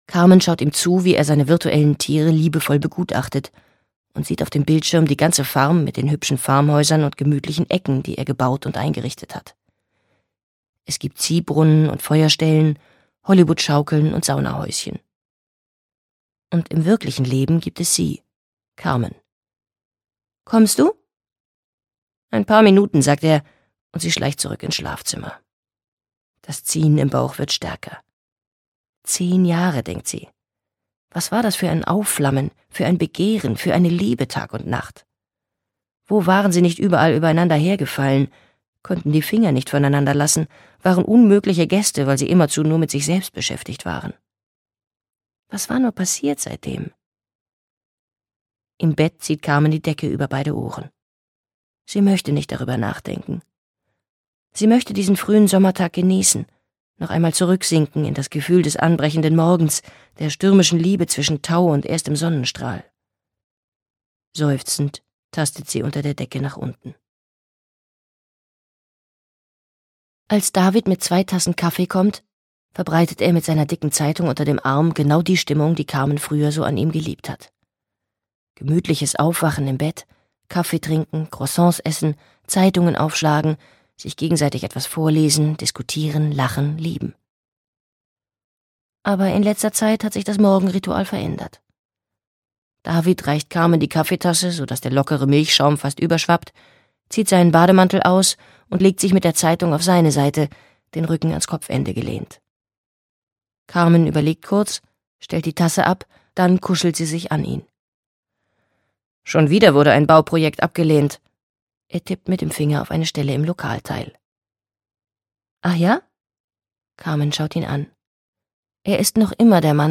Hängepartie - Gaby Hauptmann - Hörbuch